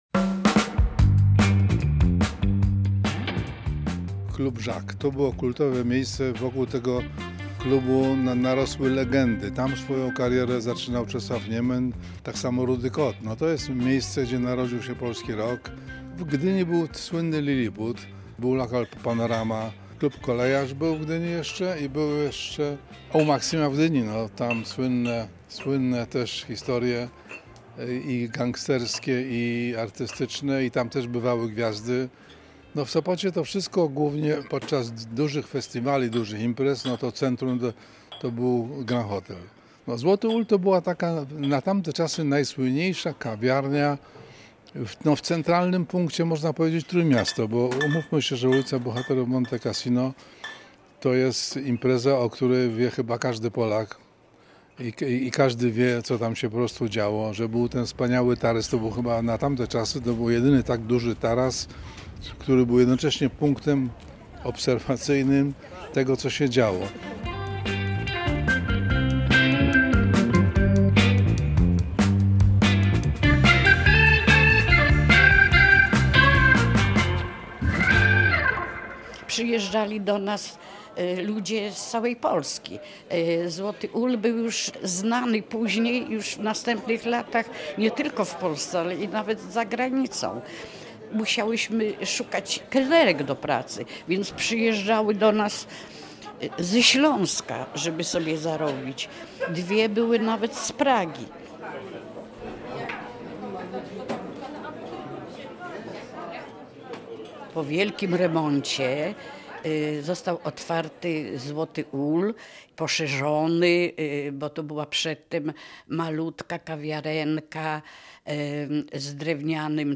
Reportaż „Był sobie Ul”, czyli wspomnienie dawnych lat